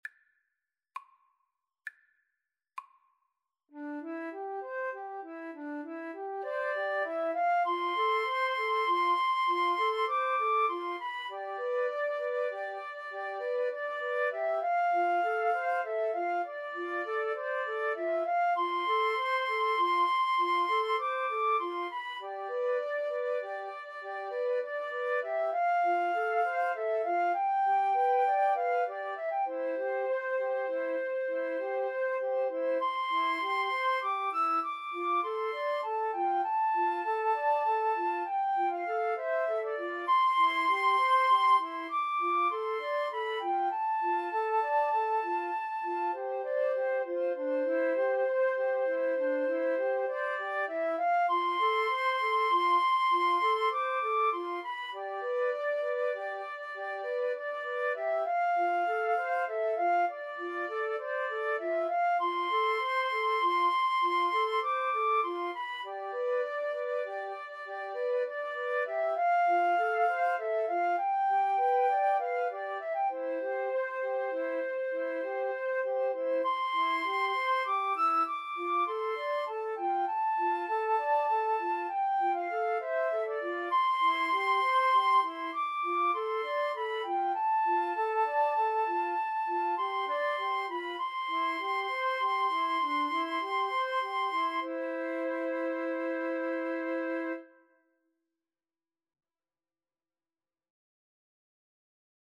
6/8 (View more 6/8 Music)
Classical (View more Classical Flute Trio Music)